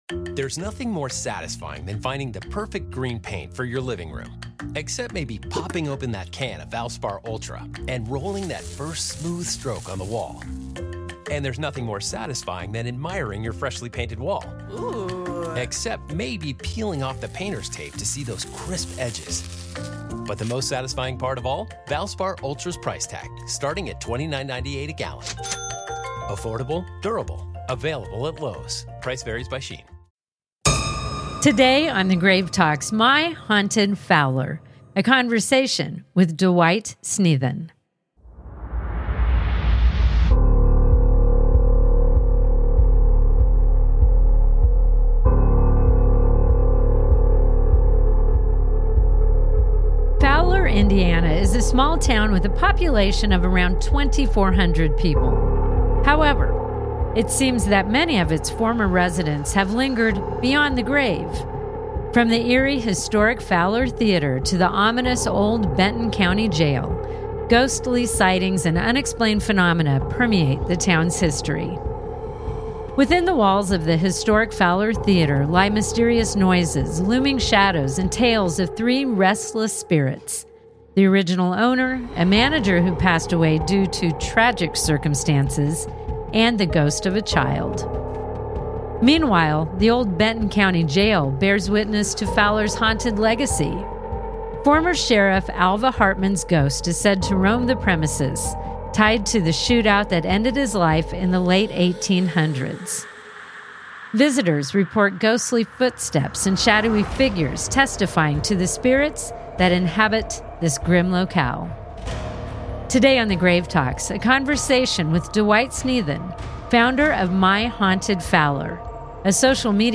Today on the Grave Talks, a conversation